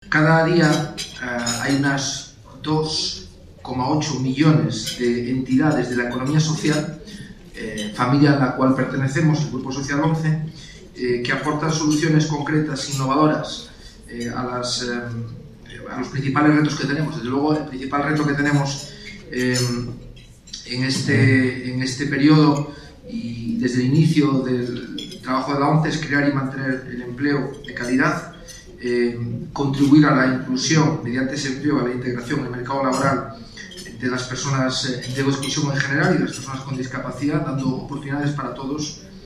Embajadores, cónsules y consejeros de embajadas participaron en la Embajada de Francia en España en el VI Encuentro Diplomacia para la Inclusión organizado por el Grupo Social ONCE y la Academia de la Diplomacia, bajo el patrocinio del embajador francés en nuestro país, que ostenta la presidencia semestral del Consejo de la UE.